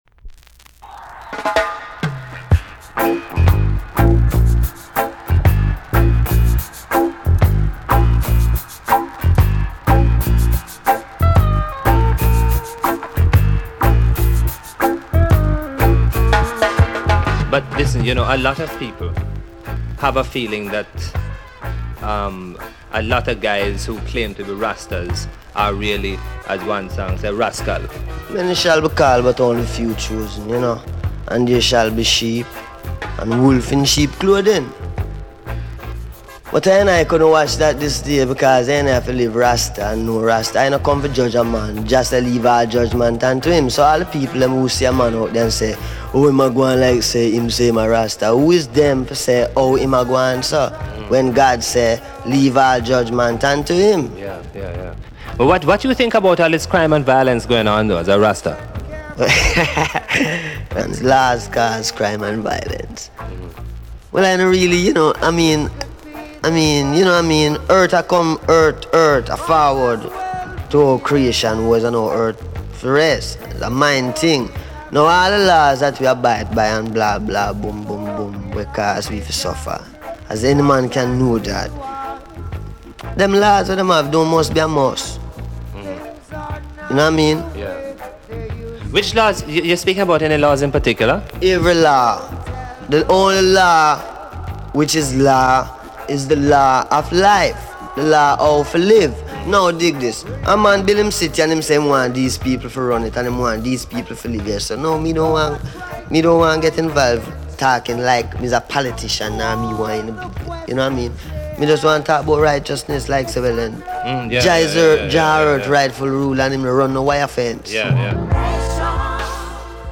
B.SIDE EX- 音はキレイです。
B.1 Interview